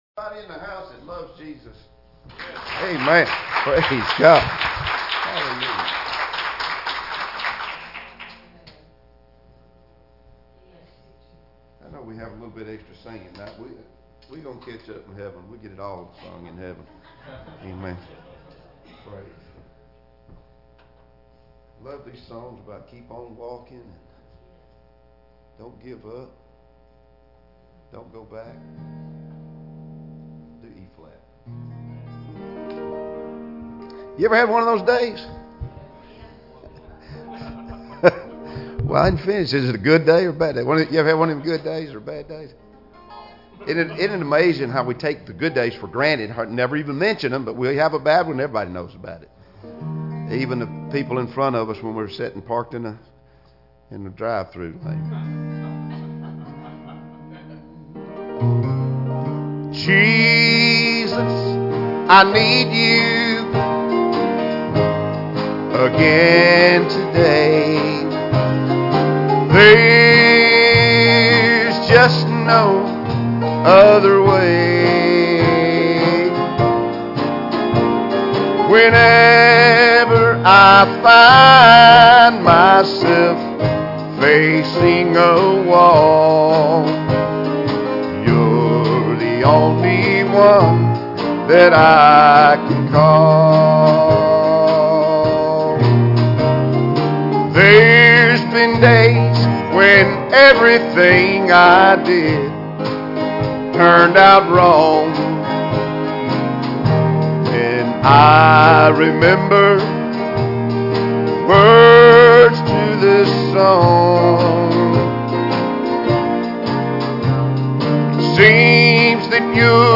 Passage: "Joshua 24:14-15" Service Type: Wednesday Evening Services Joshua 1:1-9